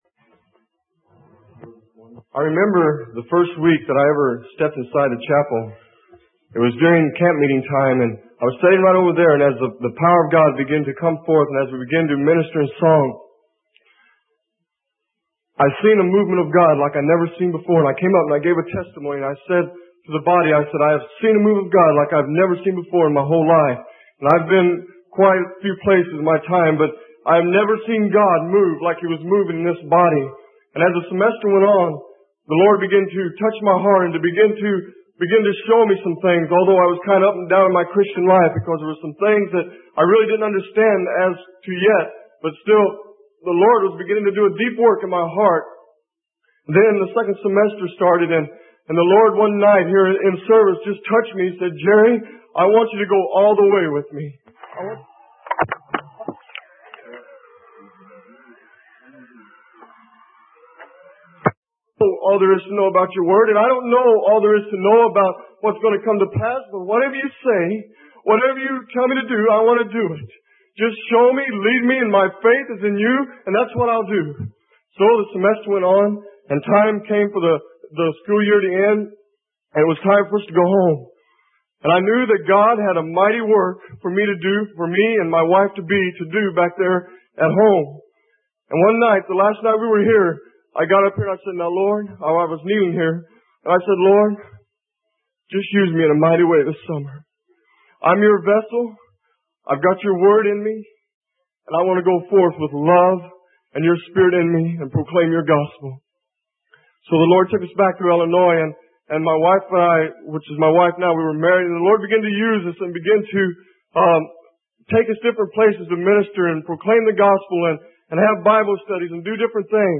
Sermon: Living Faith in Action - Freely Given Online Library